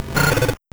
Cri de Feurisson dans Pokémon Or et Argent.